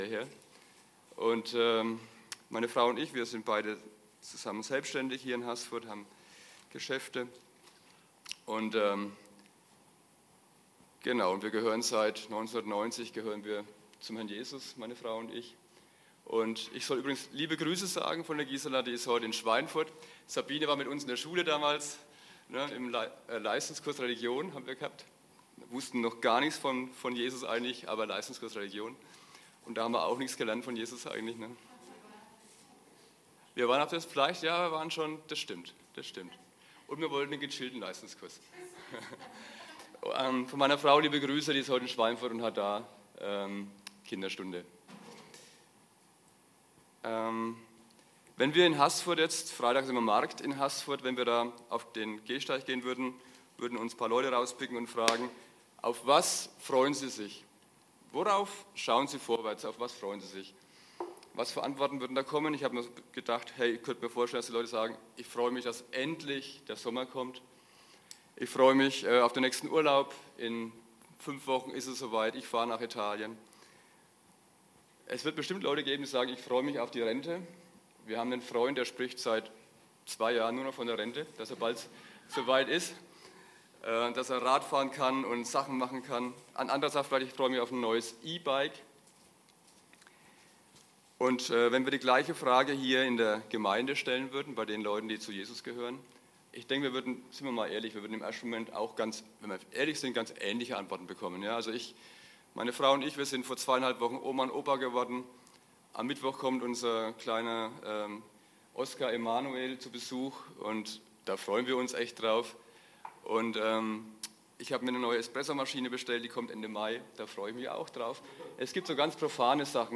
Predigt Entrückung